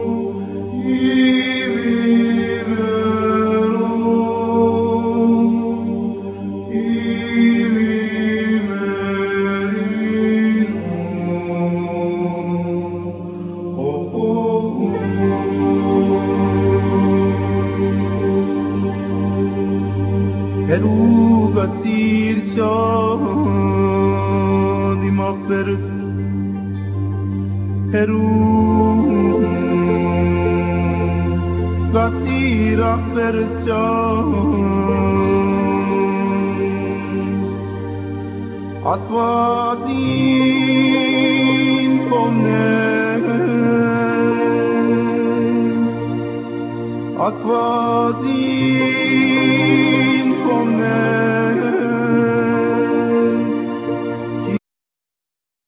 Vocal,Sazabo,Duduk,Percussion
Oud,Cumbus,Sazbus,Keyboards